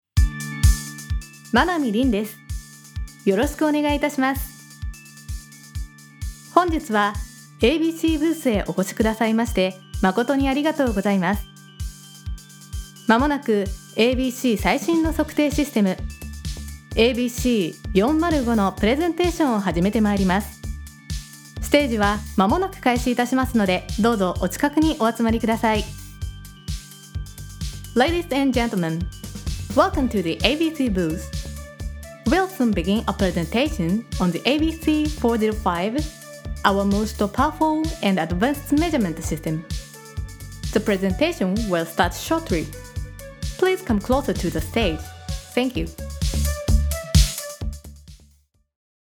ナレーター｜MC